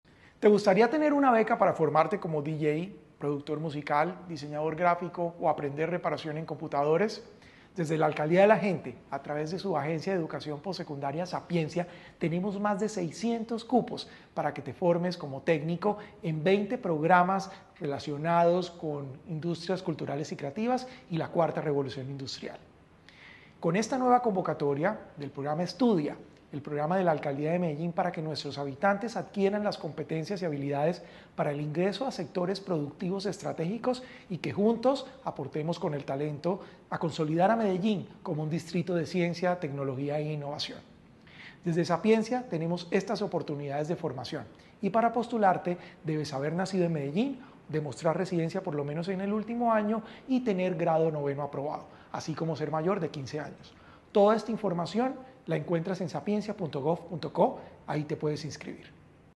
Declaraciones del director general de Sapiencia, Salomón Cruz Zirene
Audio-Declaraciones-del-director-general-de-Sapiencia-Salomon-Cruz-Zirene-3.mp3